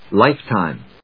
音節life・time 発音記号・読み方
/lάɪftὰɪm(米国英語)/